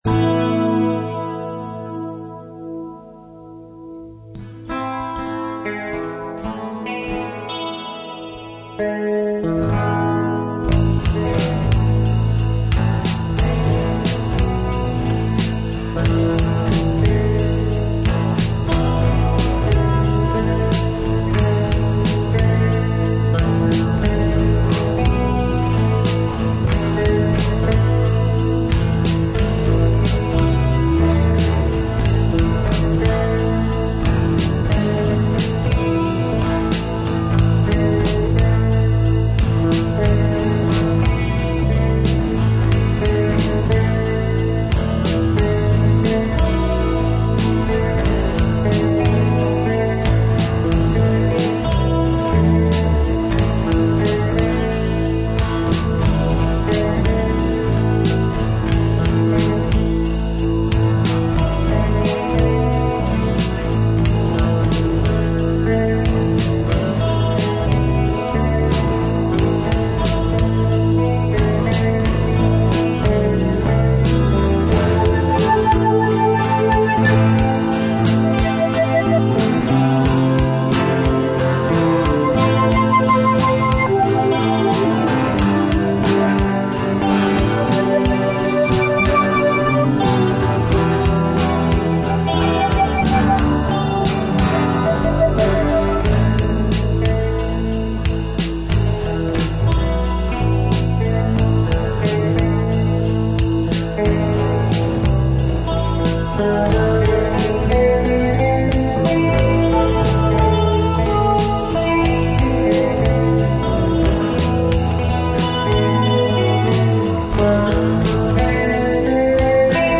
KEY = G, 4/4, 130BPM